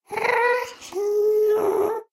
sounds / mob / ghast / moan6.ogg
moan6.ogg